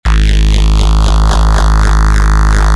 drum & bass samples
Bass Tones 20 G#
Bass-Tones-20-G.mp3